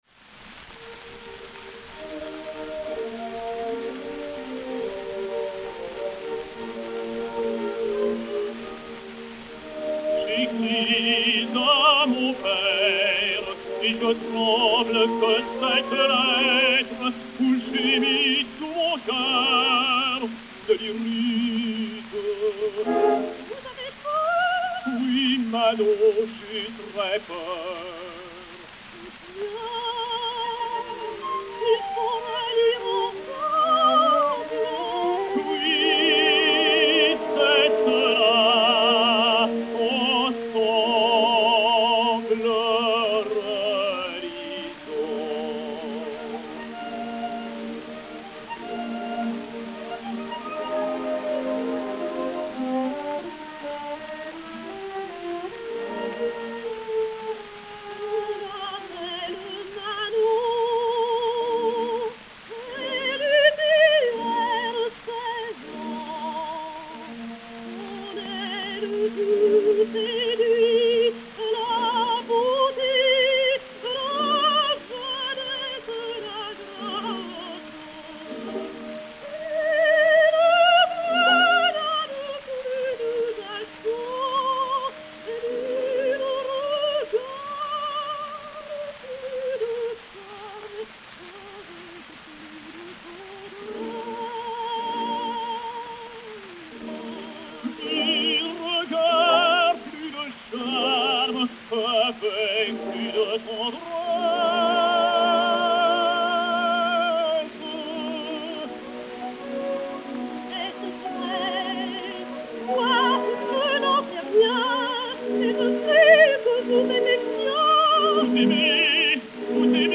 Ninon Vallin (Manon), Léon Beyle (Des Grieux) et Orchestre